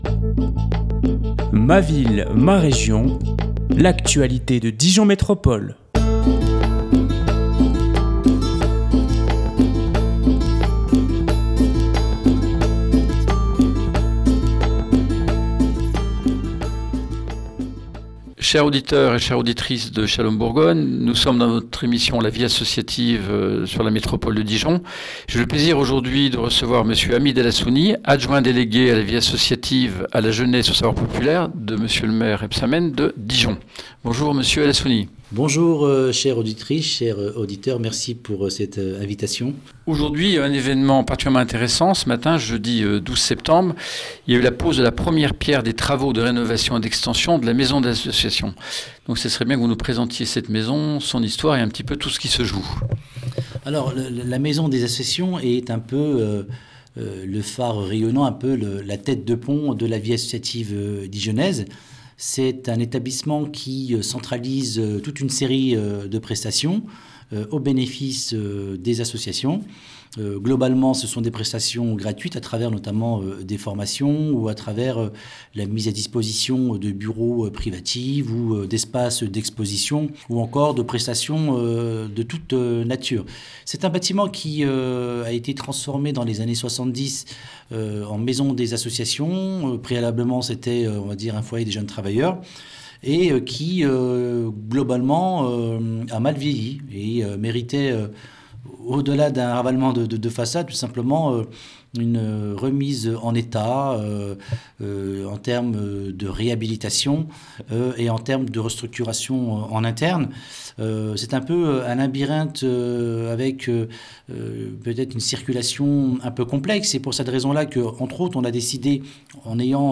Radio Shalom Bourgogne était présent lors de la pose de la première pierre de la rénovation et de l'extension de la Maison desAssociations.
Hamid El Hassouni, Adjoint délégué à la vie associative, à la jeunesse et aux savoirs populaires,a répondu à nos questions à l'occasion de cet évènement, réaffirmant, en particulier, le soutien dela Ville de Dijon à la vie associative locale.
Interview